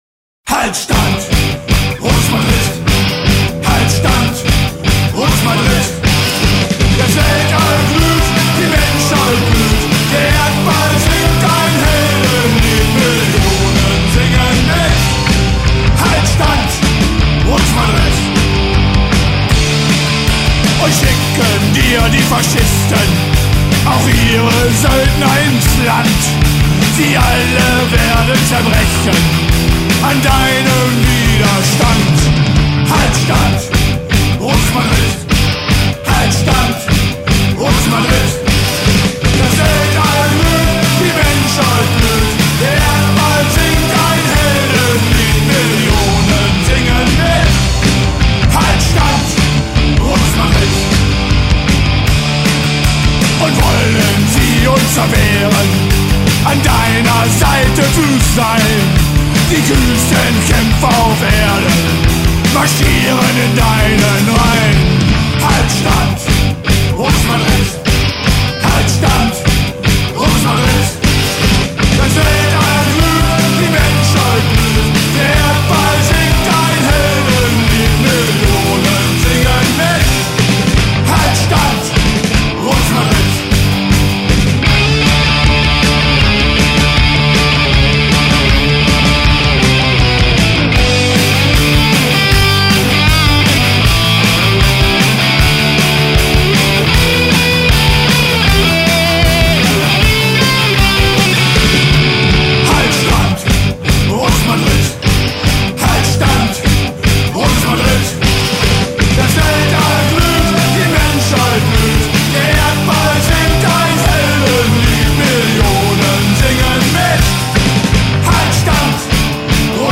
немецкая маршевая песня